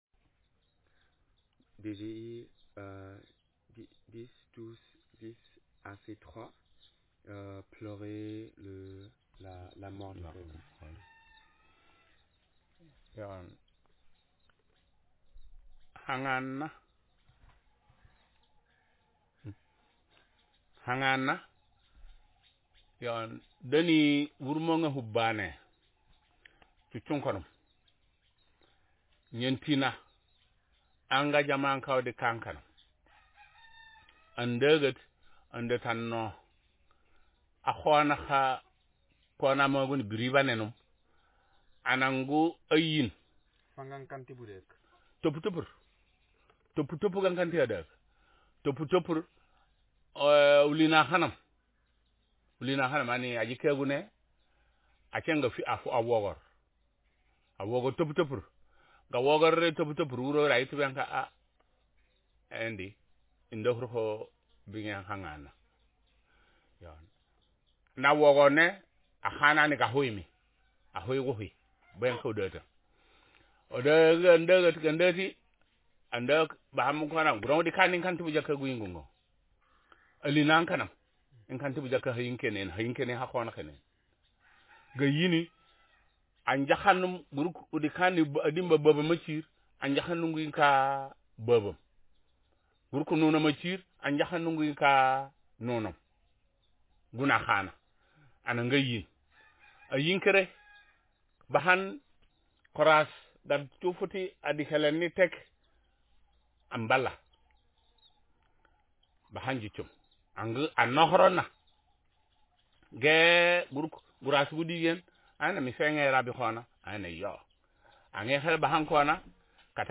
Speaker age70
Speaker sexm
Text genreprocedural